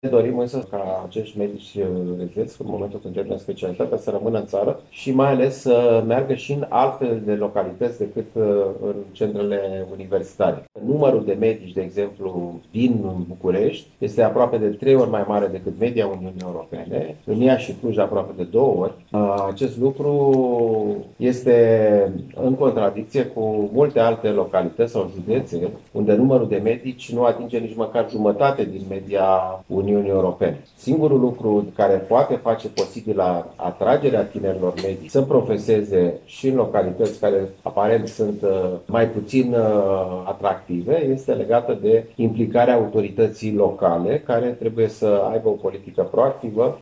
Ministrul Sănătăţii, Alexandru Rafila, a declarat, astăzi, la Iași, că este necesar ca şi autorităţile din localităţile mici să se implice în atragerea medicilor tineri.